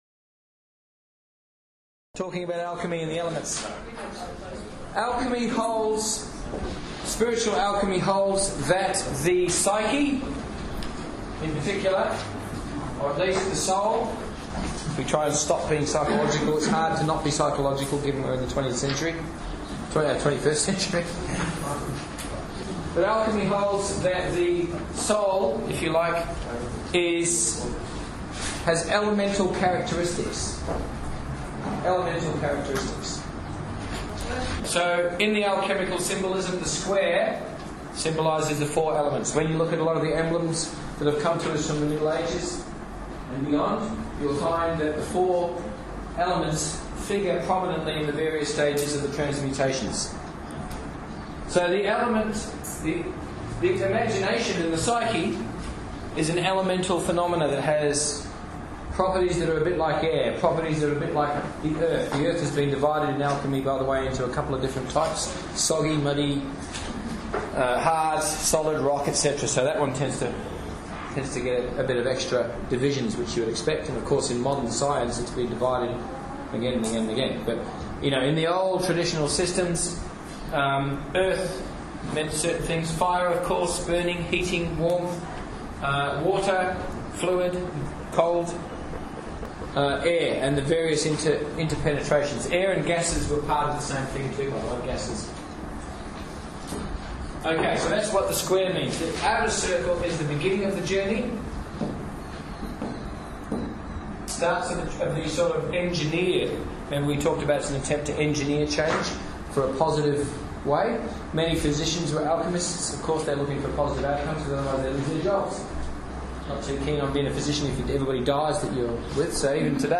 The Alchemical Imagination (Lecture Sample)